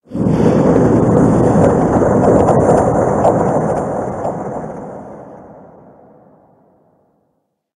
Cri de Monthracite Gigamax dans Pokémon HOME.
Cri_0839_Gigamax_HOME.ogg